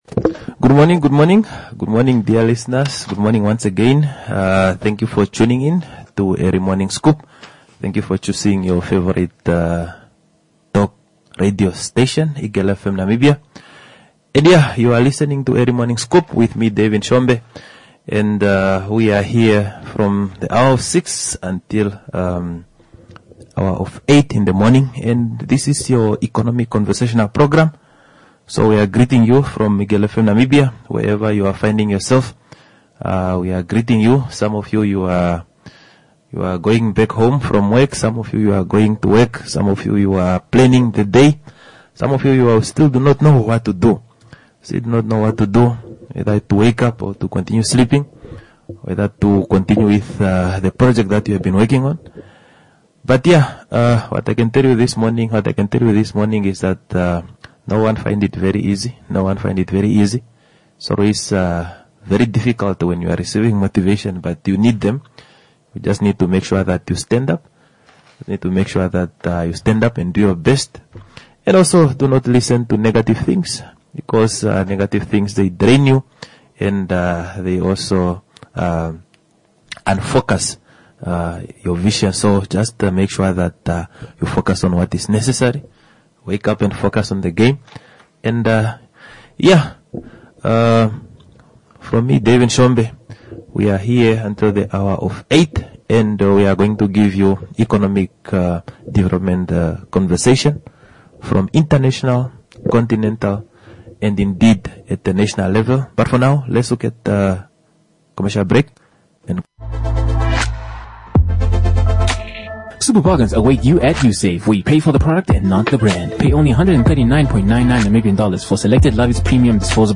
In conversation with activist